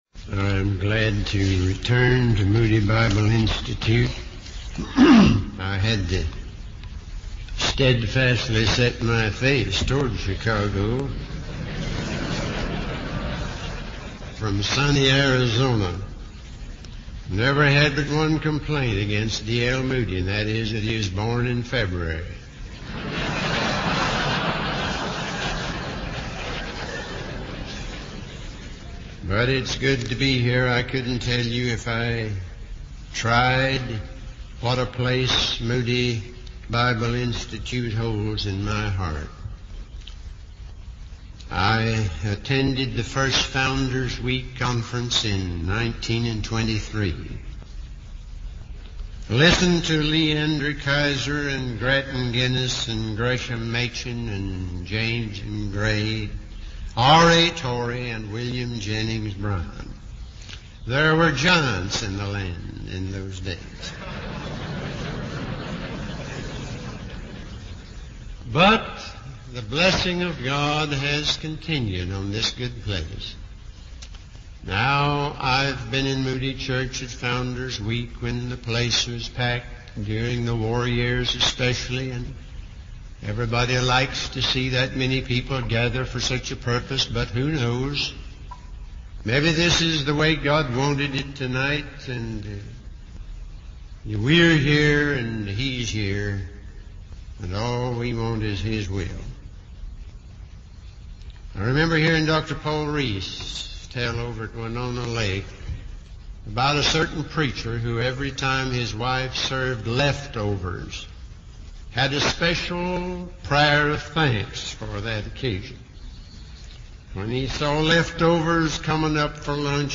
In this sermon, the speaker uses the analogy of a water wheel in an old-fashioned mill to illustrate the importance of removing hindrances and obstacles in order for the church to operate effectively. He emphasizes the need for repentance and the removal of sin in order for the church to be successful in evangelism. The speaker also highlights the danger of trying to do things in our own strength, rather than relying on the power of the Holy Spirit.